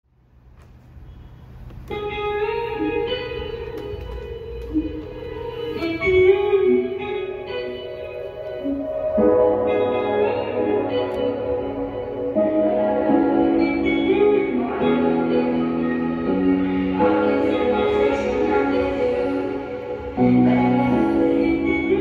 Звук клубной музыки, зажигающей ночной клуб